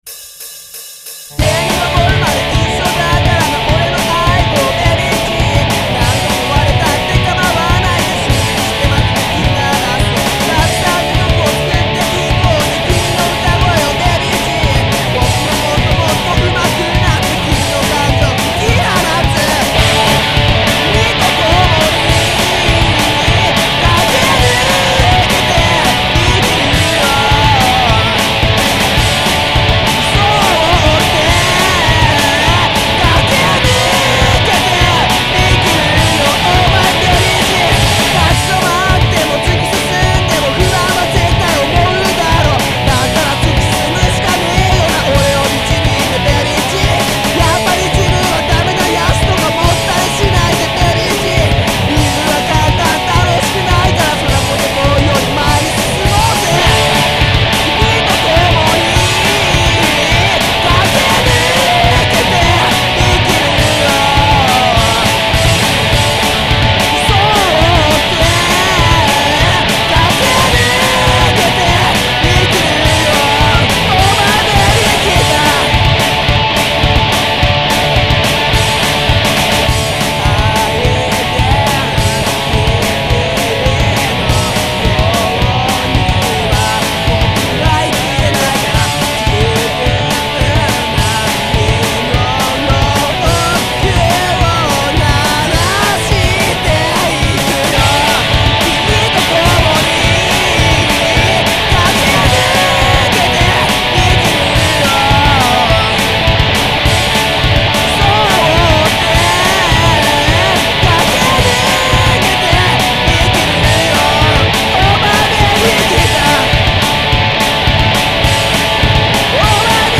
POP ROCK
大学の頃から宅録で作ってきた曲を、
babyguitar1.mp3